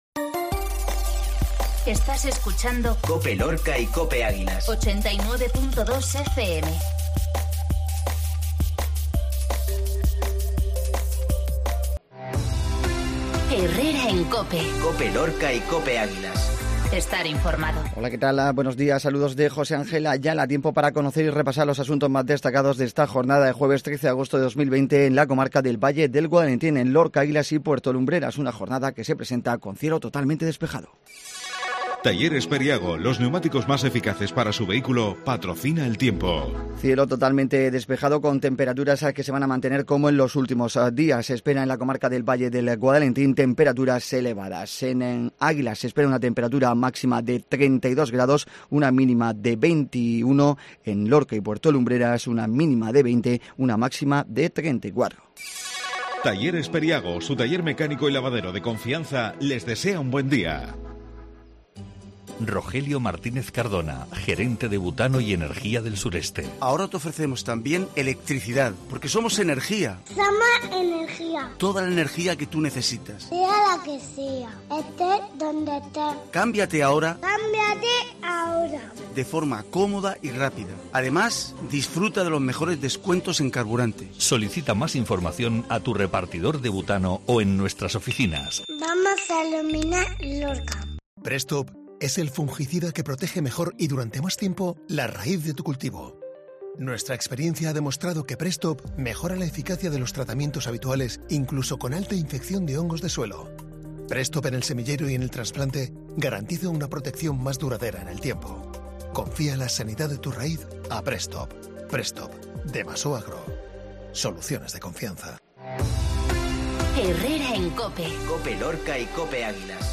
INFORMATIVO MATINAL JUEVES